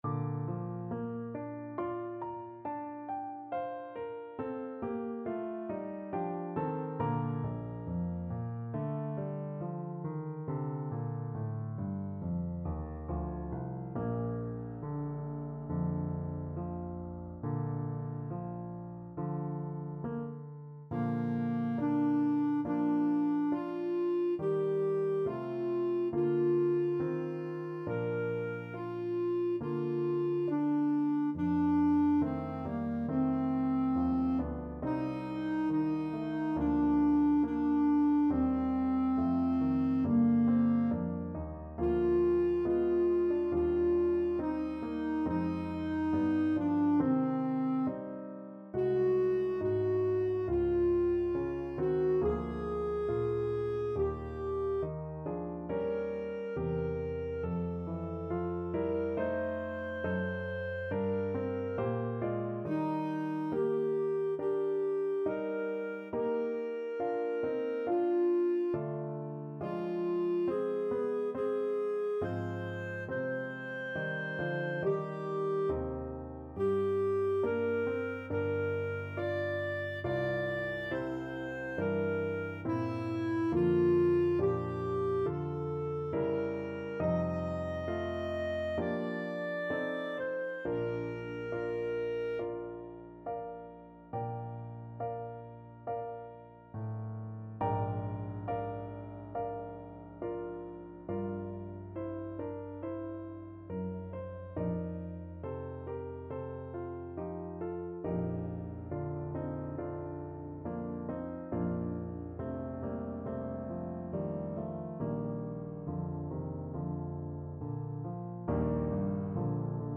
Classical Humperdinck, Engelbert Abends will ich schlafen gehn from Hansel and Gretel Clarinet version
2/2 (View more 2/2 Music)
Slow =c.69
Bb major (Sounding Pitch) C major (Clarinet in Bb) (View more Bb major Music for Clarinet )
Classical (View more Classical Clarinet Music)